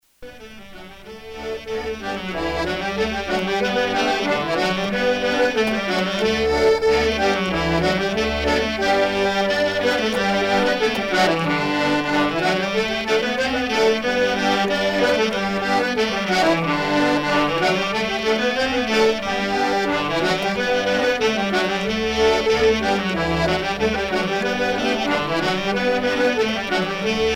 danse : aéroplane
Pièce musicale éditée